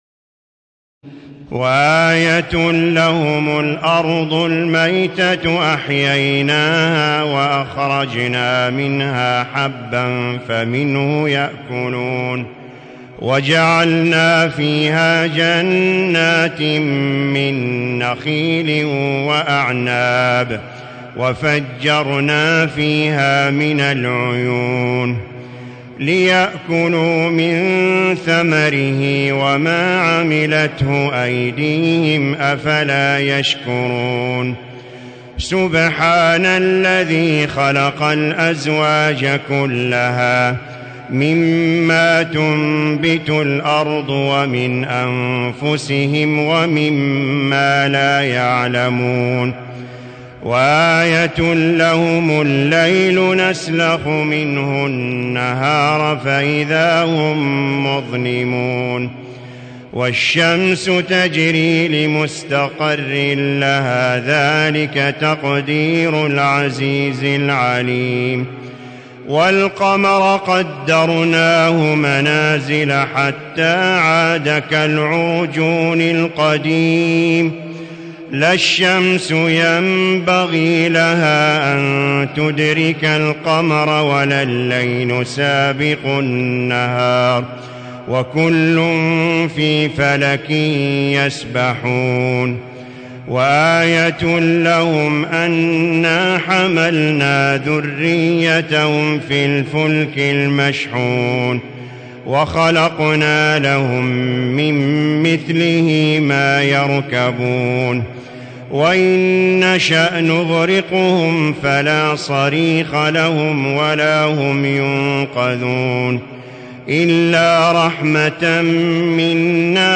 تراويح ليلة 22 رمضان 1437هـ من سور يس (33-83) والصافات (1-138) Taraweeh 22 st night Ramadan 1437H from Surah Yaseen and As-Saaffaat > تراويح الحرم المكي عام 1437 🕋 > التراويح - تلاوات الحرمين